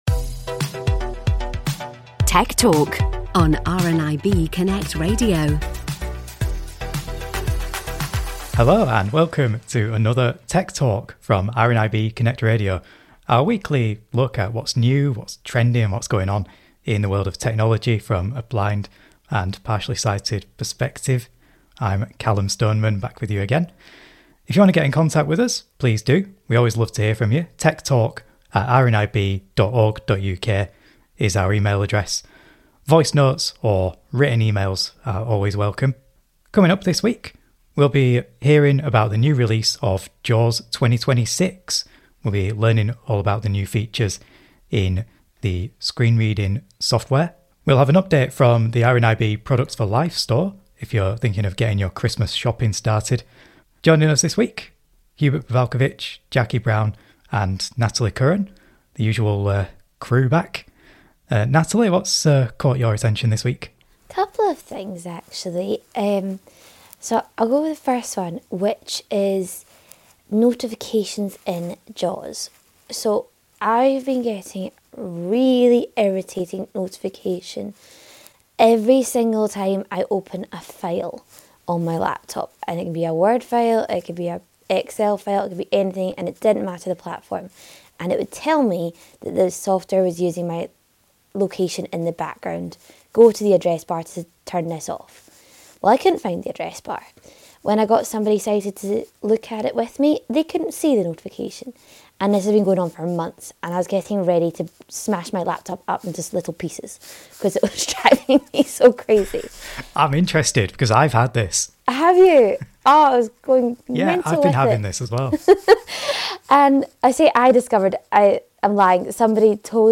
You can listen to the show live in the UK every Tuesday at 1pm on Freeview Channel 730, online at RNIB Connect Radio | RNIB , or on your smart speaker.